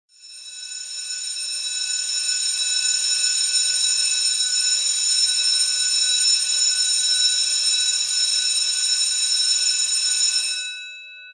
BEll
A Standard school electric bell 10 seconds long
Schoolbell10sec.mp3